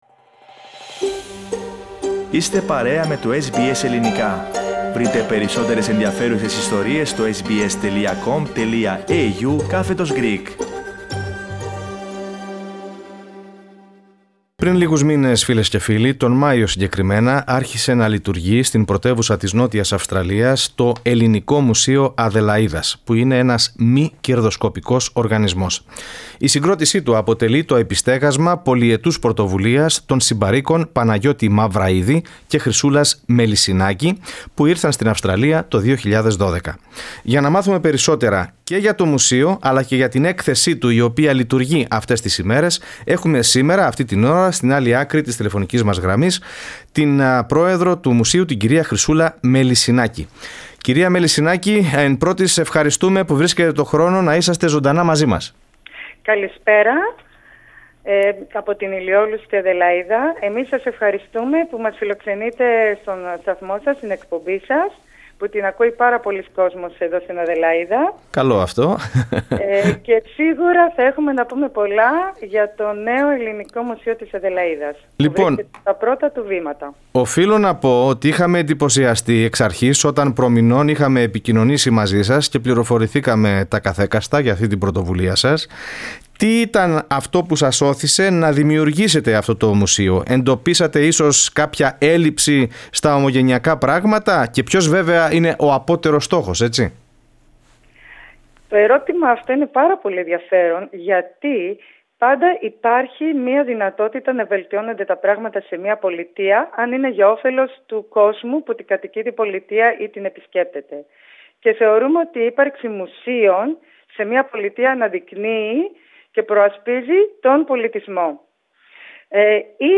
Μιλώντας στο Ελληνικό Πρόγραμμα της Δημόσιας Ραδιοφωνίας SBS